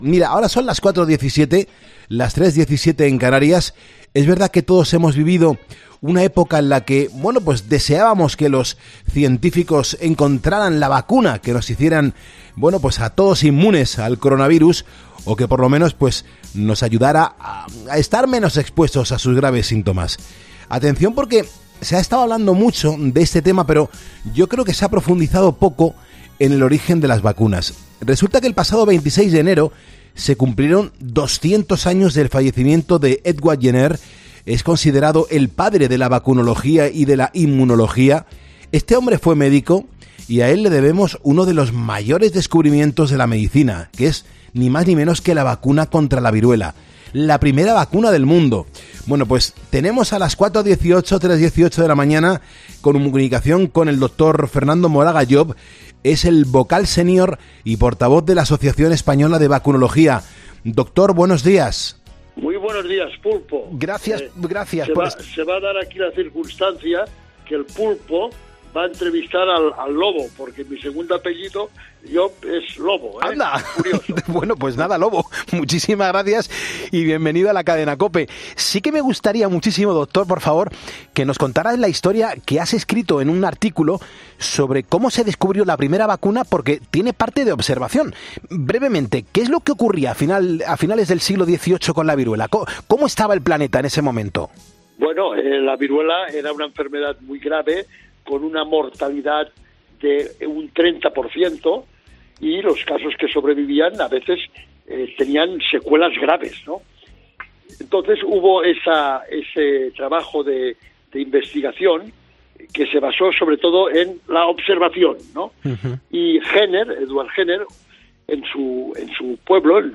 Todos los secretos de la entrevista los puedes encontrar en el audio.